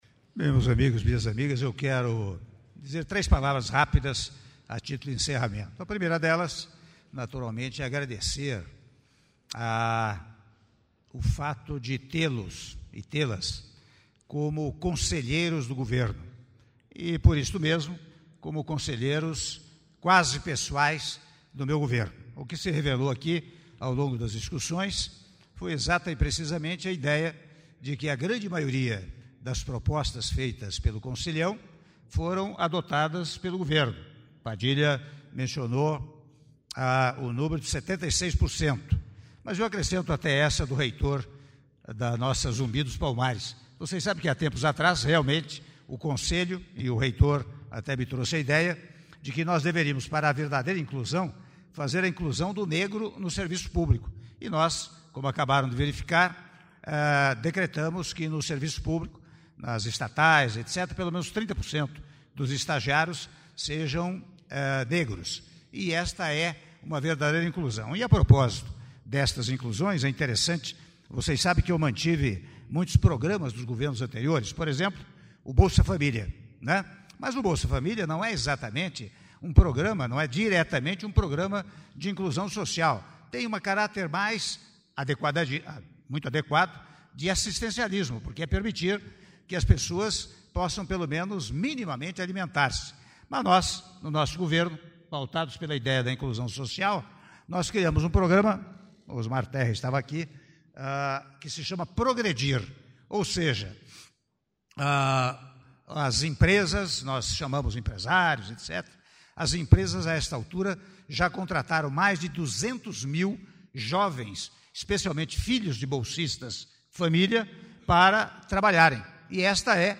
Áudio do discurso do Presidente da República, Michel Temer, durante a 48ª Reunião do Conselho de Desenvolvimento Econômico e Social - CDES - Parte 2 - Palácio do Planalto - (03min15s)